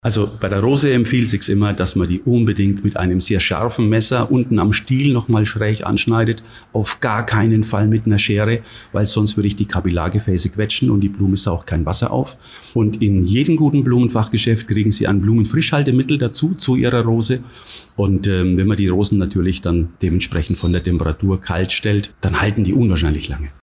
Interview: Warum feiern wir Valentinstag?